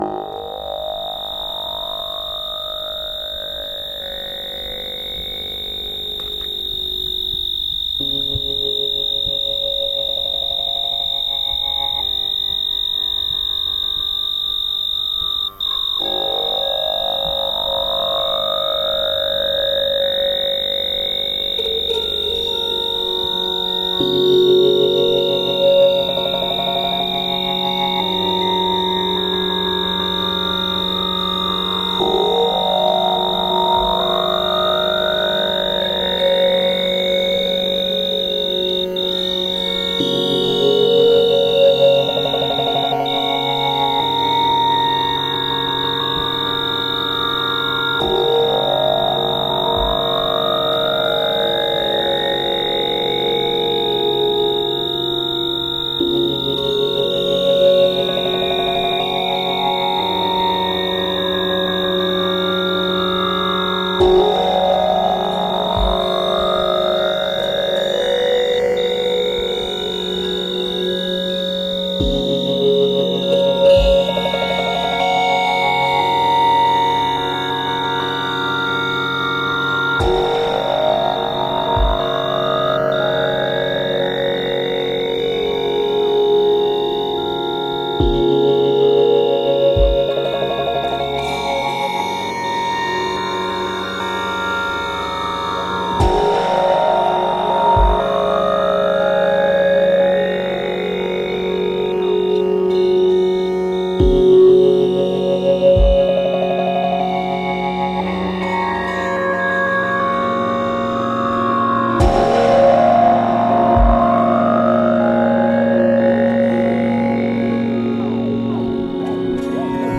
Crickets in Scopello
Headphones recommended."